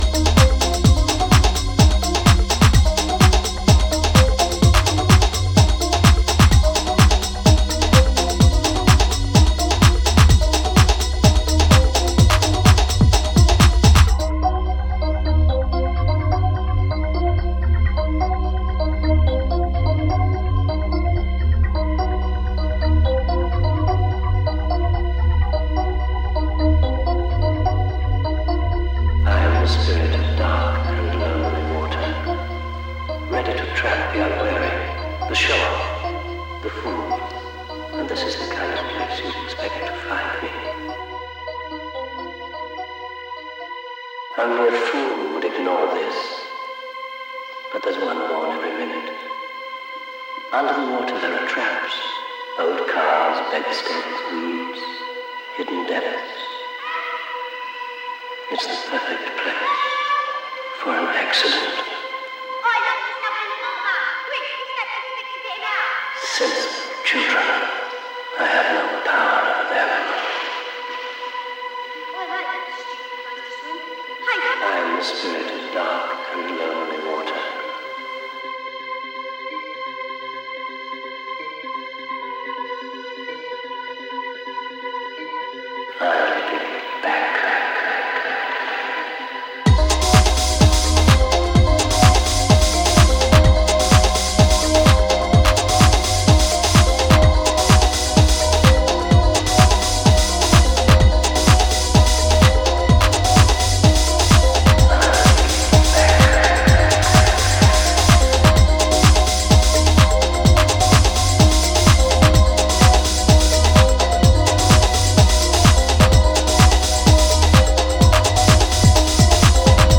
but carried by the tribal feeling the fact is undeniable.